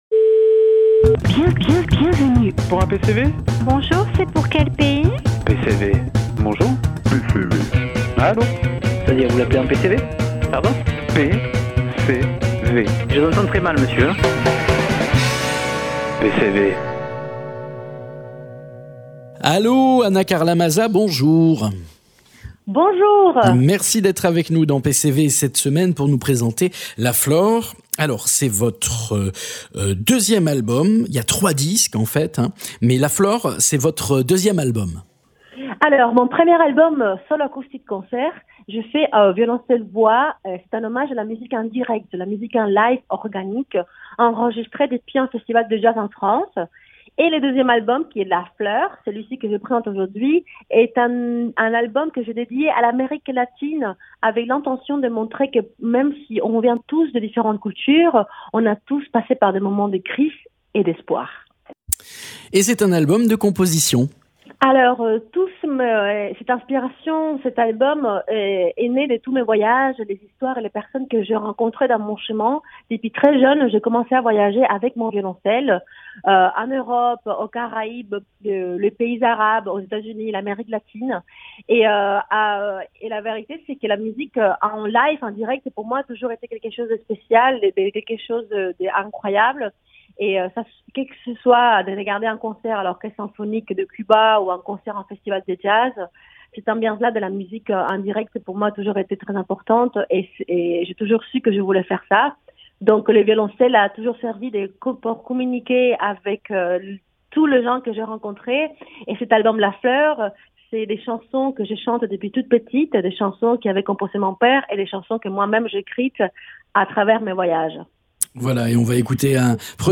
Interviews
Invité(s) : Ana Carla Maza , violoncelliste, chanteuse, compositrice et productrice